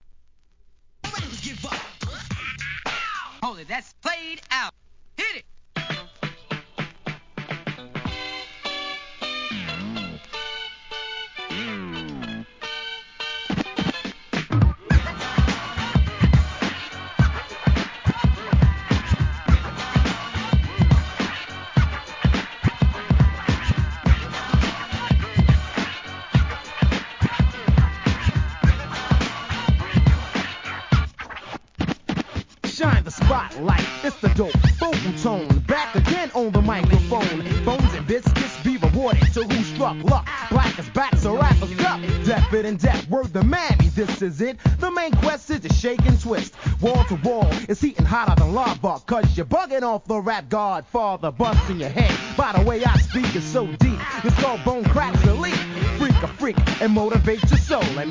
HIP HOP/R&B
VOCAL